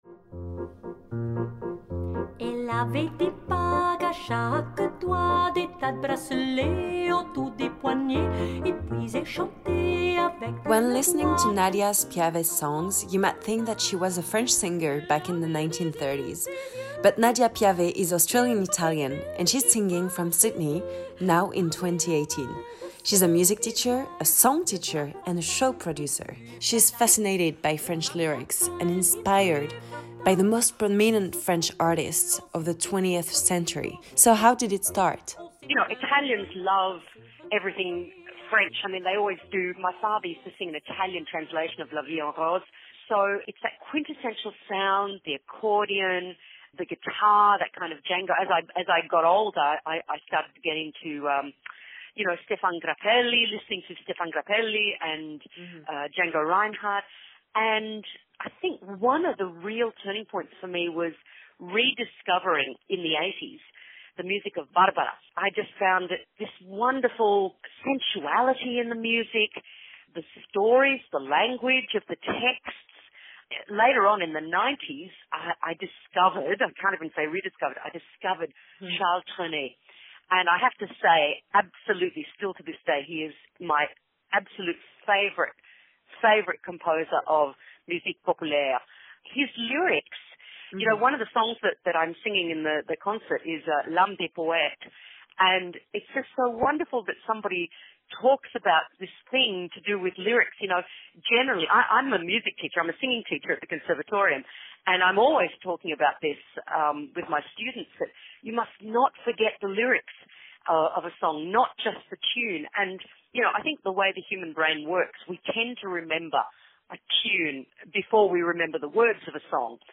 Listening to her you would bet on her being French from another era, but this incredible Italian and Australian voice will make you travel back in time.